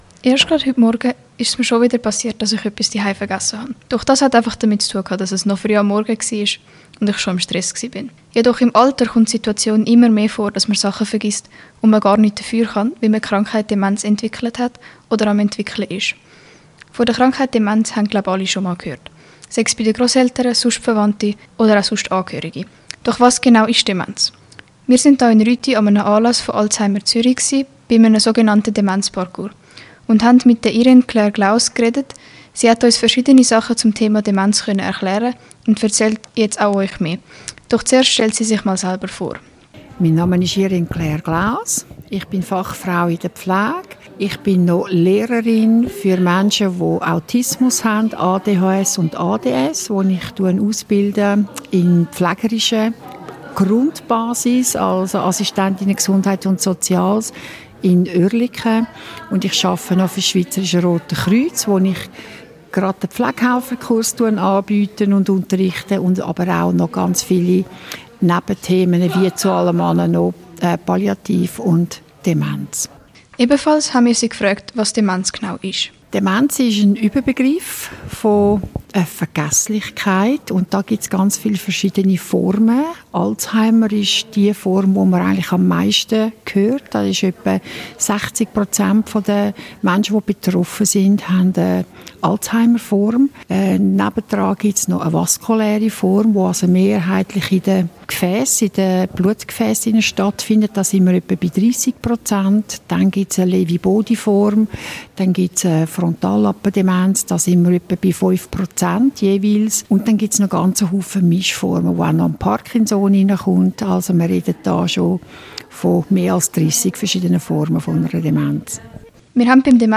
An einem Anlass von Alzheimer Zürich in Rüti hatte ich die Gelegenheit, am sogenannten Demenzparcours teilzunehmen. Dort konnte ich erleben, wie sich der Alltag für Menschen mit Demenz anfühlen kann.